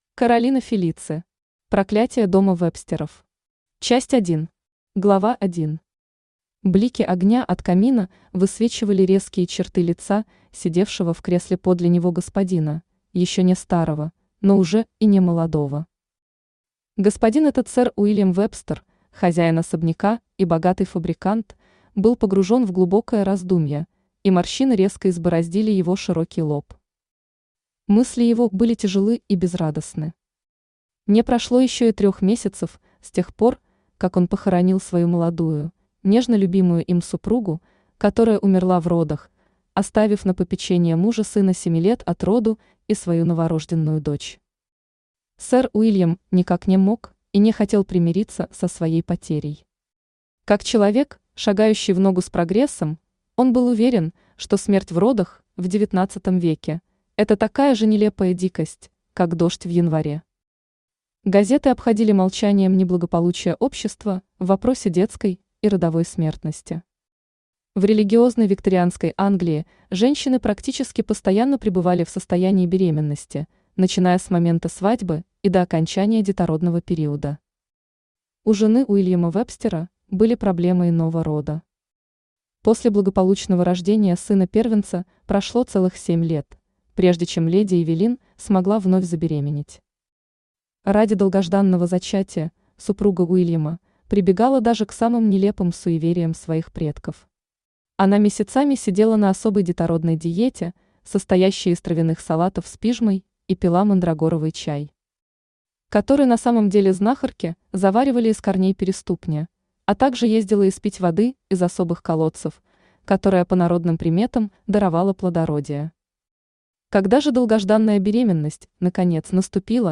Аудиокнига Проклятие дома Вебстеров | Библиотека аудиокниг
Aудиокнига Проклятие дома Вебстеров Автор Каролина Фелицы Читает аудиокнигу Авточтец ЛитРес.